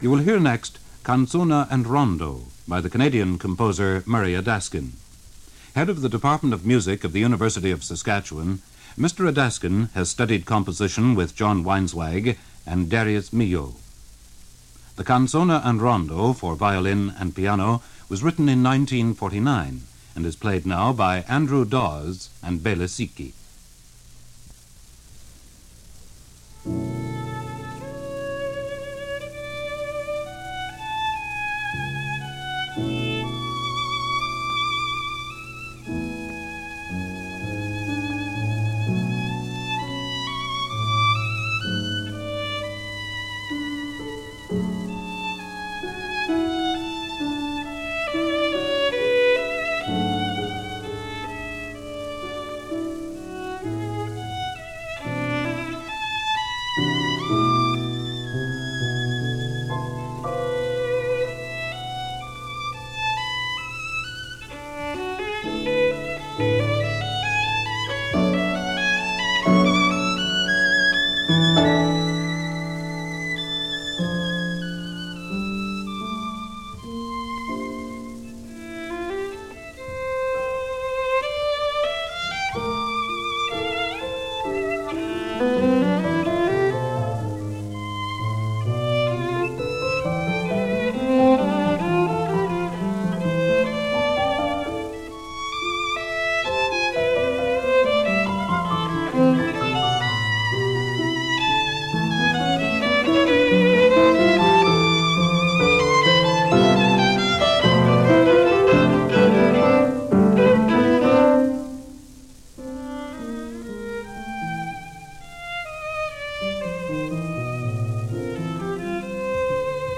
Vital, Witty and recognizably Canadian.
violin
piano